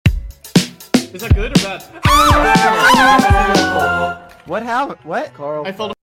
Grito